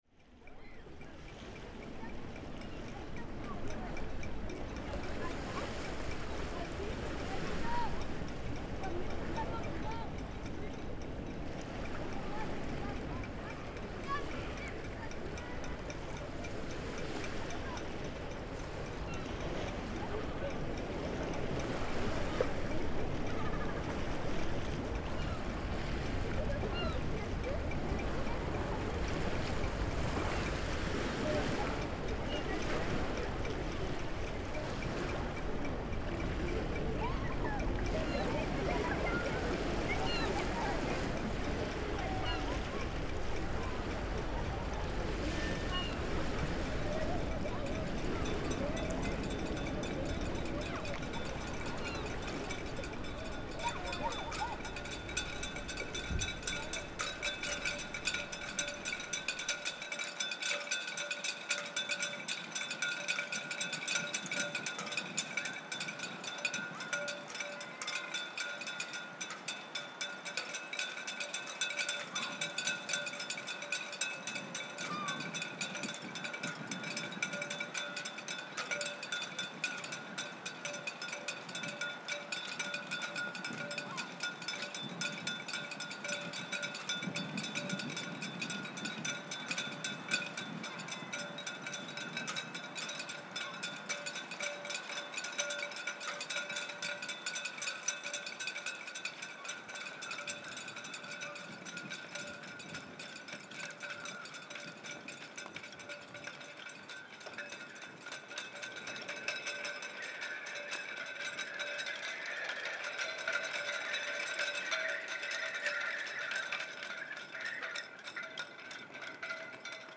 Halyards in the wind
At the slightest breath of wind (i.e. almost constantly), their halyards flap against the masts, producing a rhythm that is both regular and constantly changing.
Montage of 3 sound recordings taken at different times.